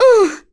Yuria-Vox_Damage_03.wav